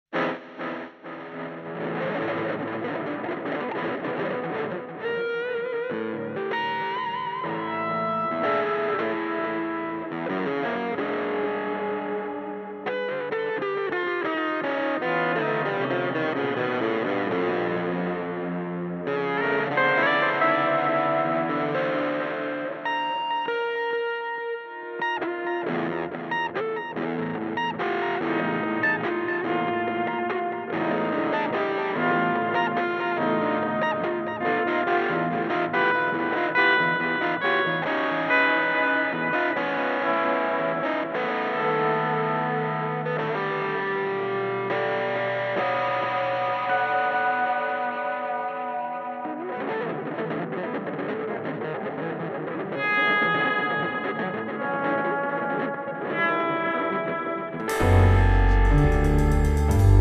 batteria
sassofoni e flauto (1, 2, 3, 4, 7, 8, 10, 11)